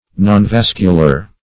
Search Result for " nonvascular" : The Collaborative International Dictionary of English v.0.48: Nonvascular \Non*vas"cu*lar\, a. (Anat.) Destitute of vessels; extravascular.
nonvascular.mp3